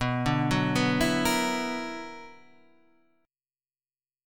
B Minor Major 11th